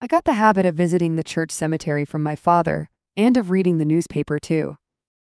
Text-to-Speech
Synthetic